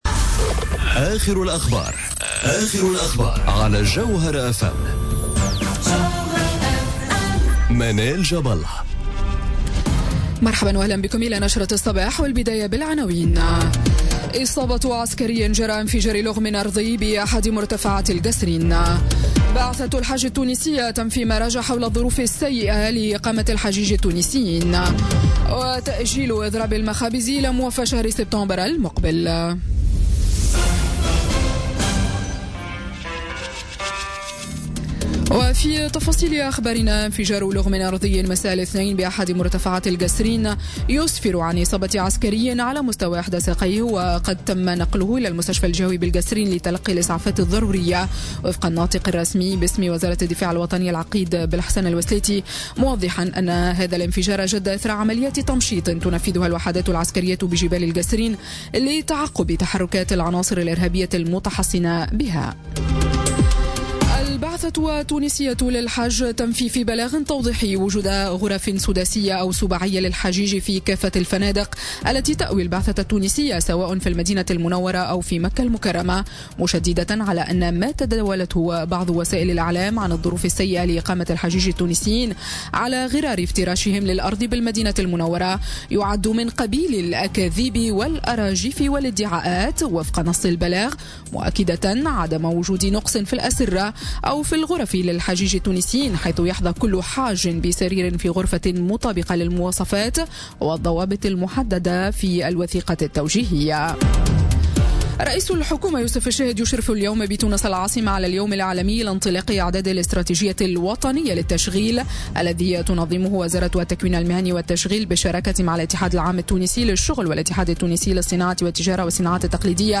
نشرة أخبار السابعة صباحا ليوم الثلاثاء 22 أوت 2017